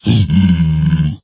zpigangry1.ogg